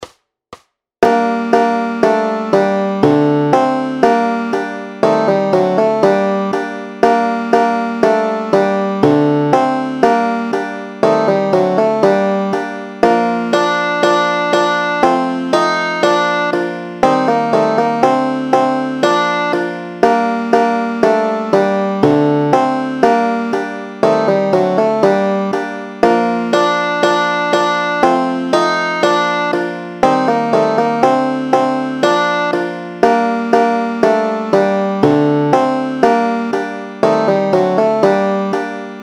Aranžmá Noty, tabulatury na banjo
Formát Banjové album
Hudební žánr Lidovky